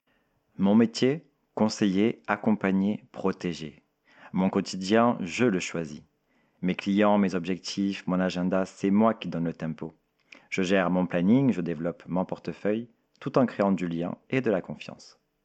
Voix off
23 - 38 ans - Baryton